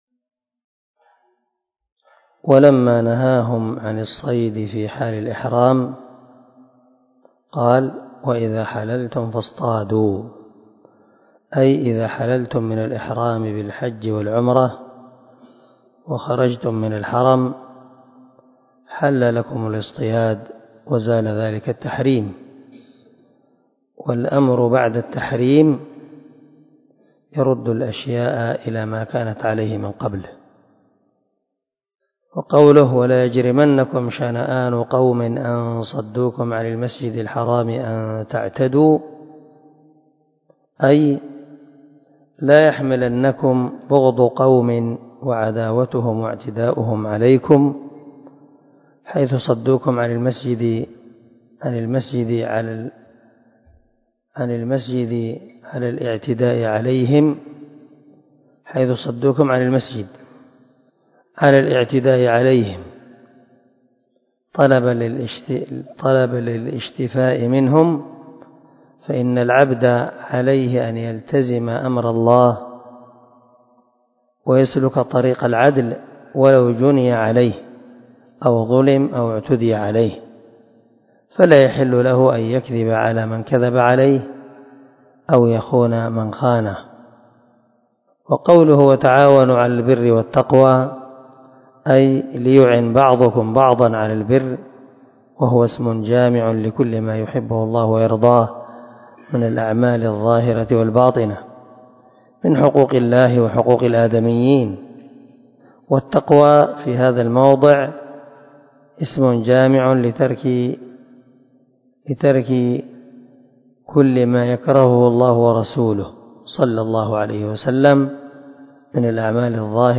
336الدرس 3 تفسير آية ( 3 ) من سورة المائدة من تفسير القران الكريم مع قراءة لتفسير السعدي